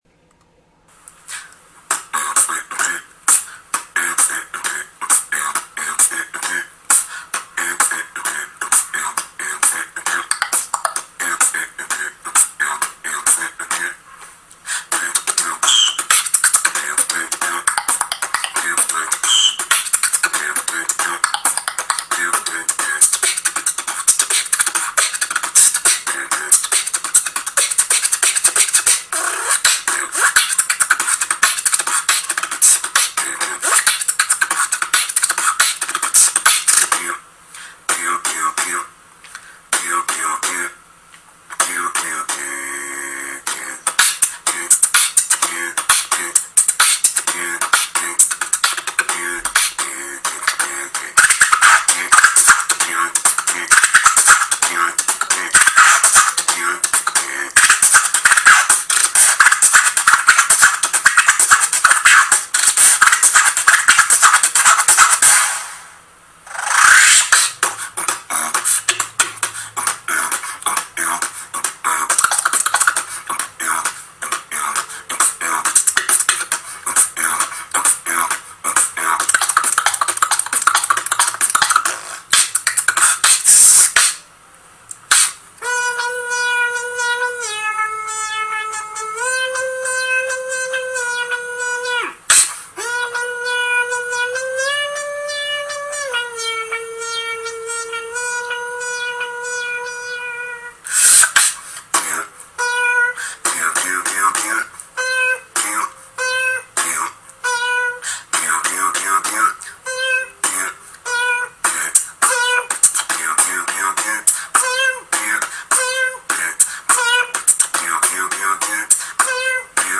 Beatboxer 2